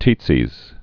(tētsēz) or Tie·tze syndrome (-tsē)